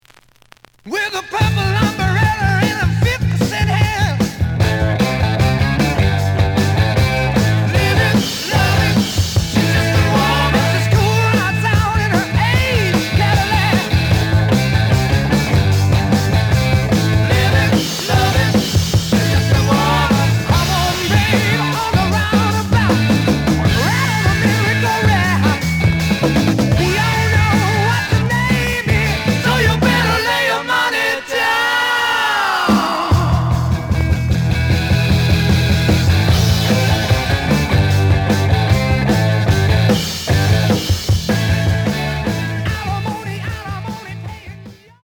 試聴は実際のレコードから録音しています。
●Genre: Rock / Pop
B面のプレイはまずまず。)